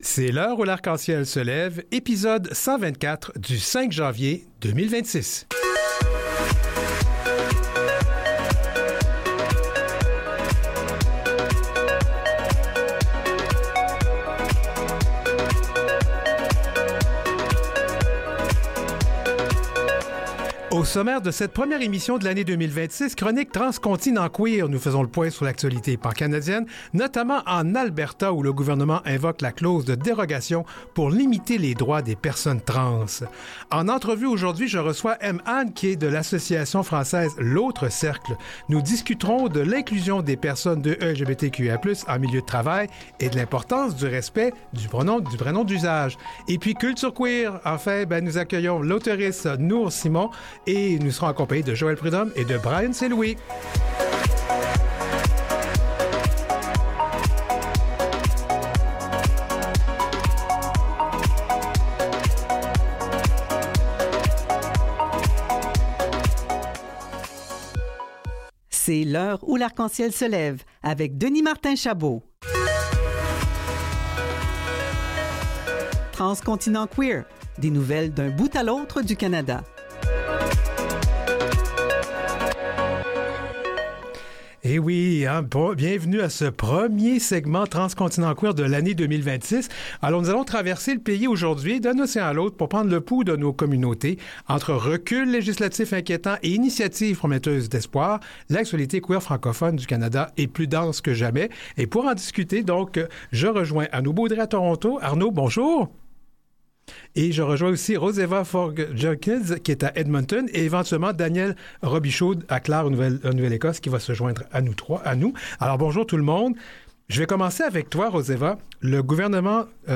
une discussion pancanadienne